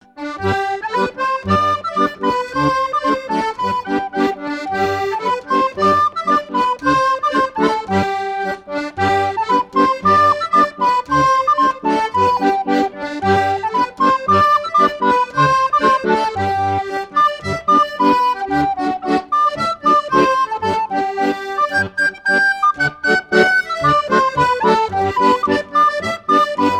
Bocage vendéen
danse : polka des bébés ou badoise
Pièce musicale éditée